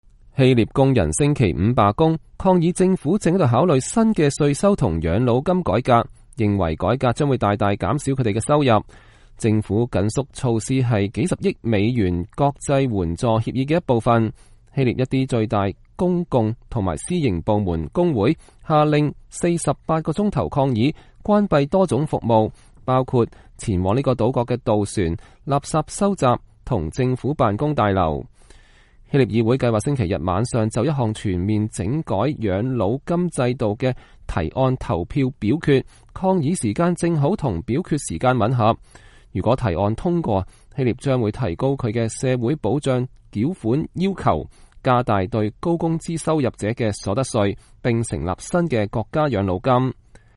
雅典罷工工人高喊口號